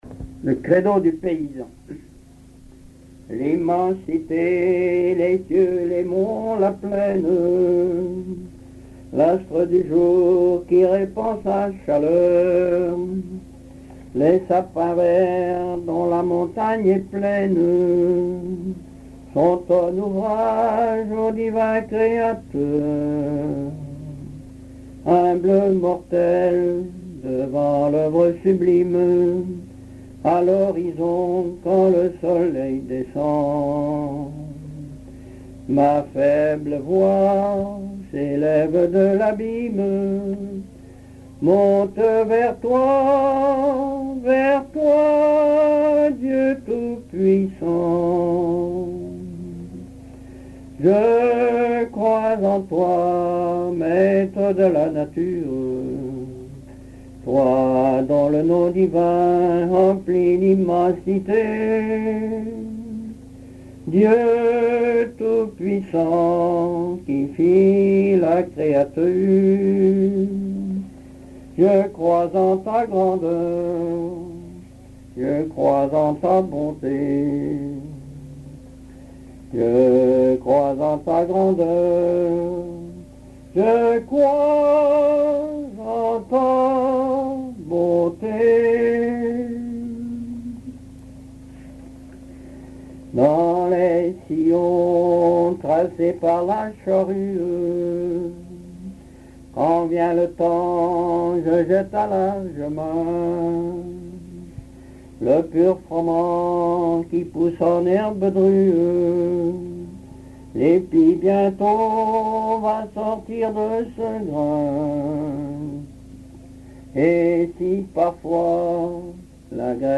Genre strophique
chansons populaires
Pièce musicale inédite